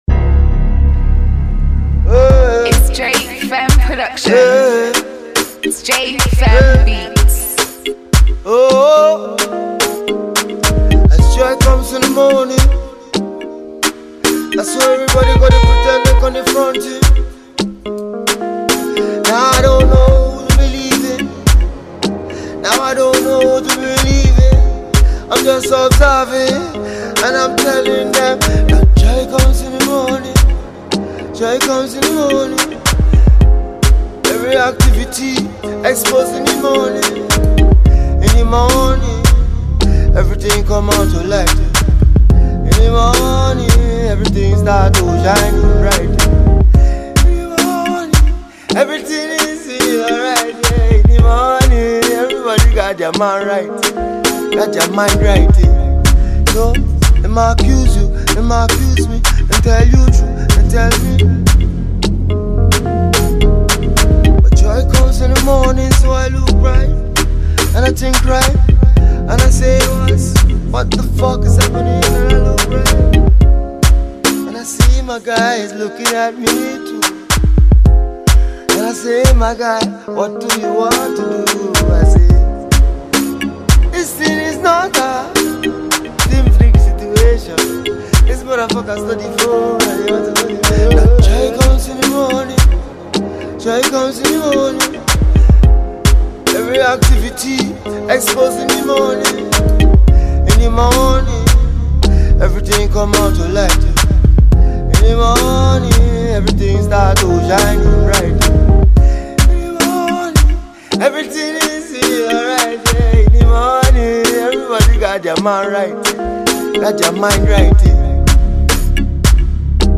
Brand new freestyle